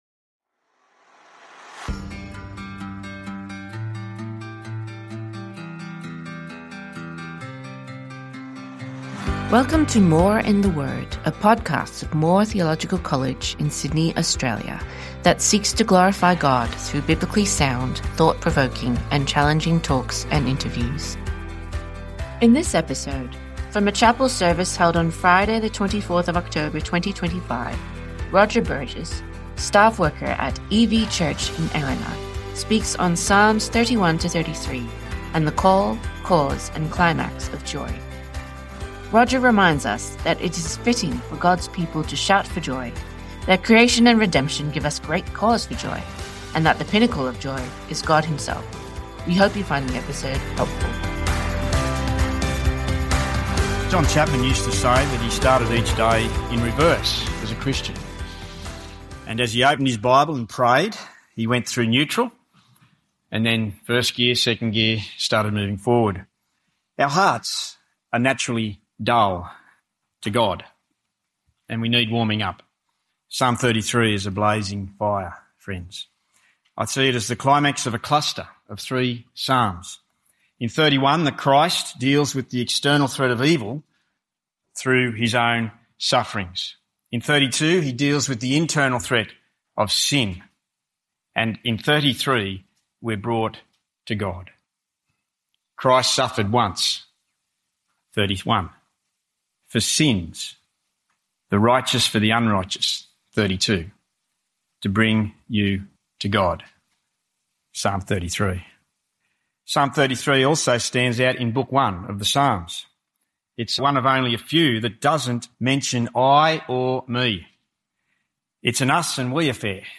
from a chapel service